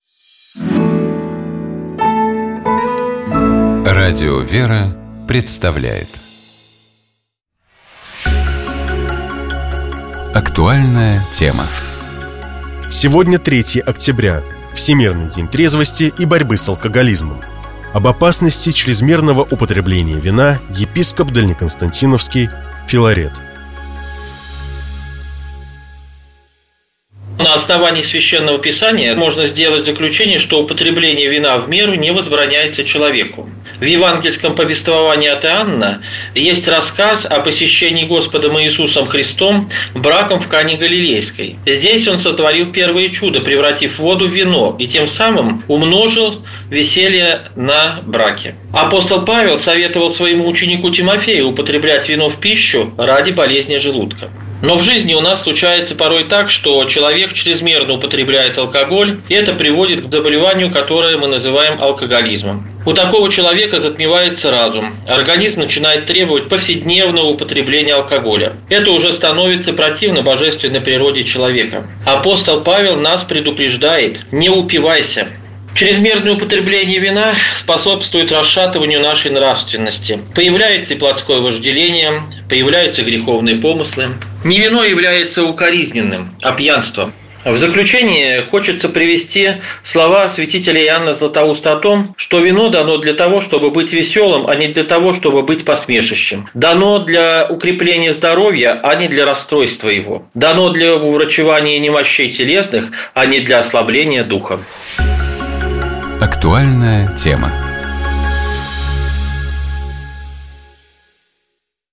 Псалом 17. Богослужебные чтения Скачать Поделиться Какой язык наиболее подходит для того, чтобы говорить о Боге?